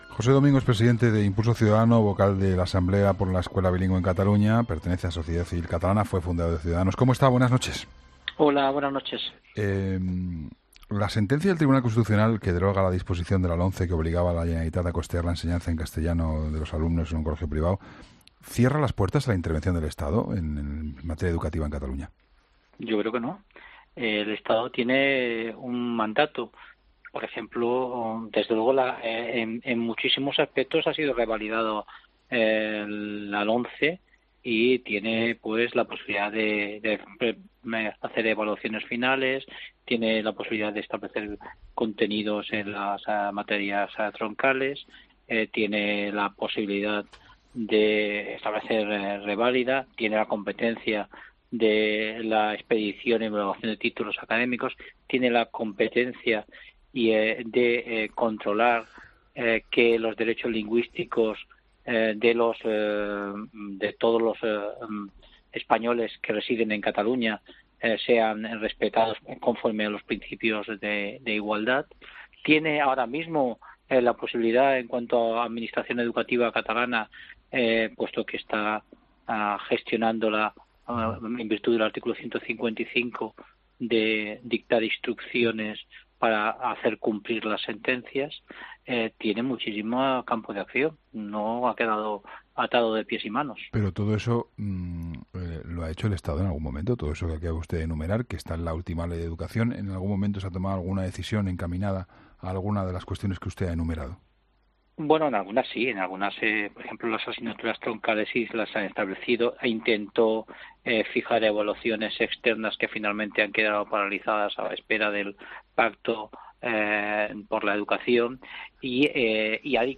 José Domingo, presidente de Impulso Ciudadano ha pedido en 'La Linterna' con Juan Pablo Colmenarejo que se cumpla la ley en Cataluña